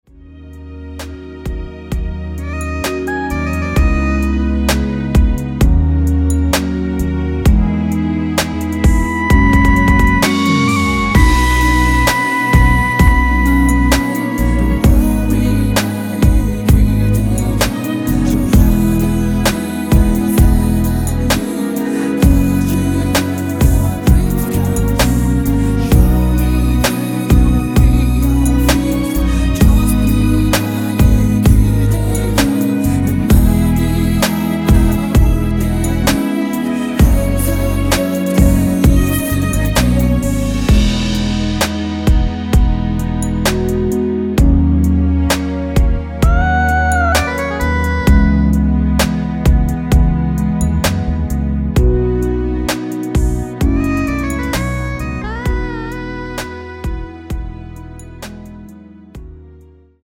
원키 코러스 포함된 MR 입니다.
앞부분30초, 뒷부분30초씩 편집해서 올려 드리고 있습니다.